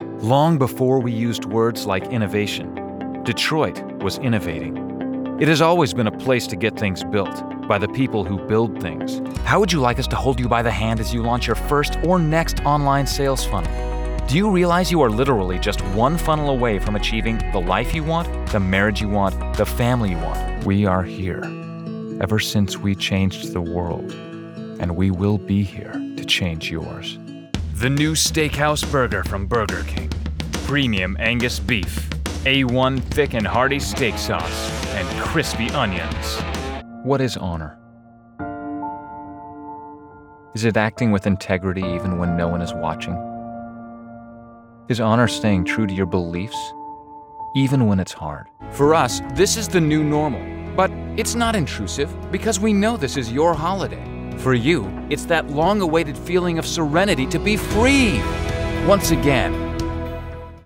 企业广告【大气沉稳】